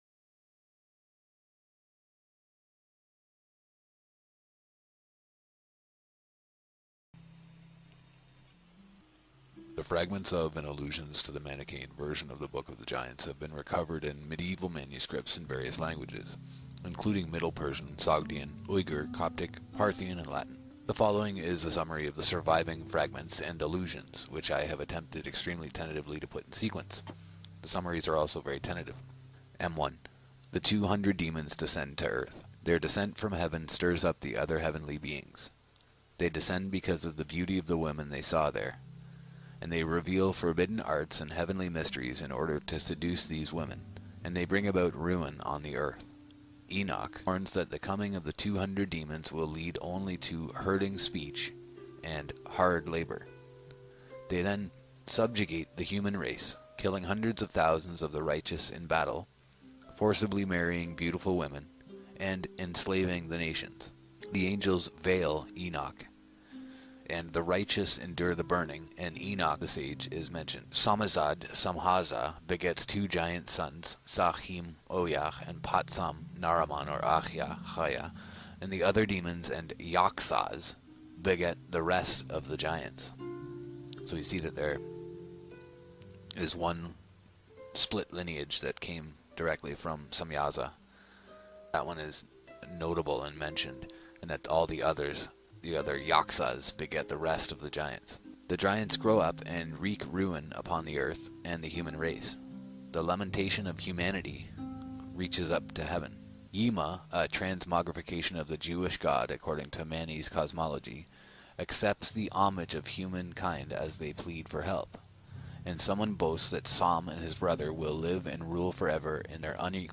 THE BOOK OF GIANTS - Pre-Flood Apocrypha UPGRADED & UPDATED! (FULL AUDIOBOOK)